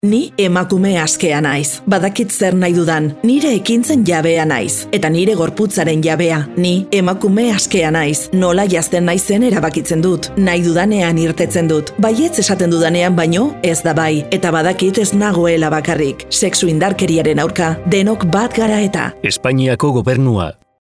Demos  de grabaciones realizadas por el equipo de locutores de Escena Digital. Voces para  el doblaje de documentales.